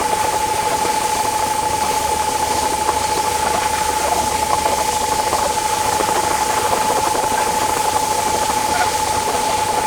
Sfx_tool_spypenguin_move_damage_01.ogg